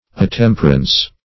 Search Result for " attemperance" : The Collaborative International Dictionary of English v.0.48: Attemperance \At*tem"per*ance\, n. [Cf. OF. atemprance.] Temperance; attemperament.